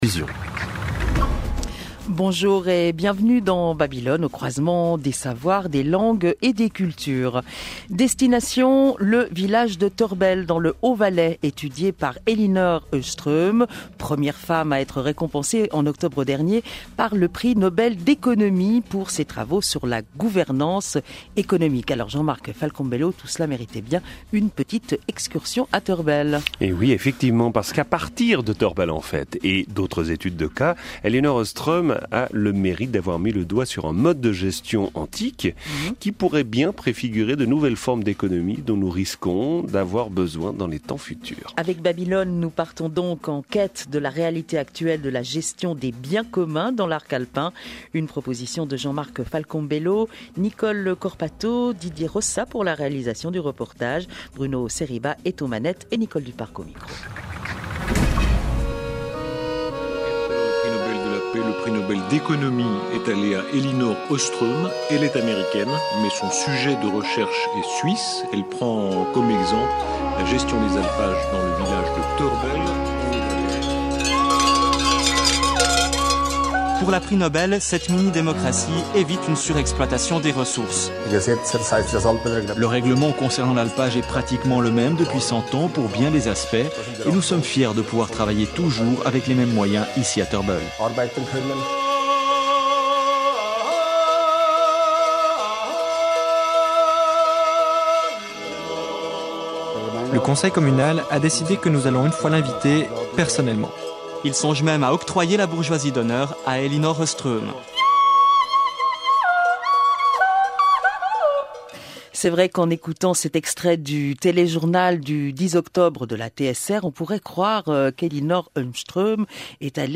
Emission radiophonique de la Radio Suisse Romande : Babylone
Un modèle archaïque pour des réalités futures. Reportage autour des travaux d’Elinor Oström, Prix Nobel d’Economie